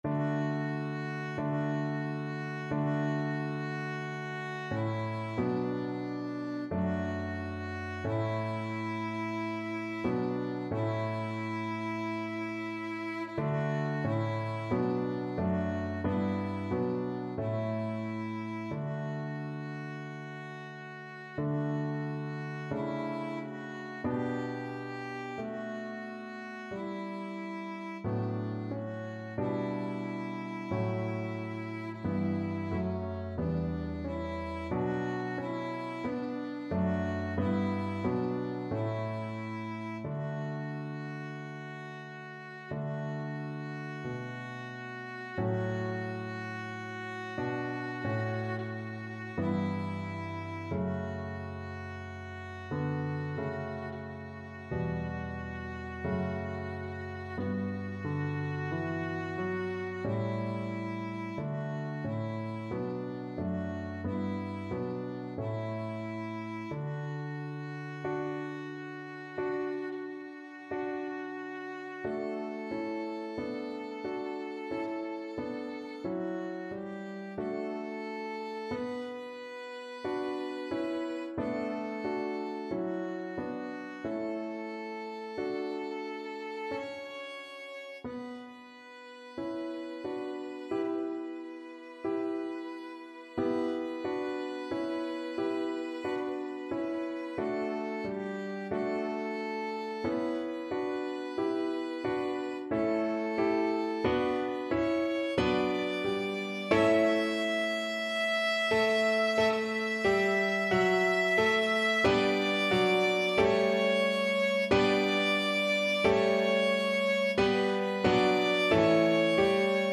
4/4 (View more 4/4 Music)
Smoothly, not quick =45
Classical (View more Classical Violin Music)